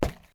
Footstep_Wood_05.wav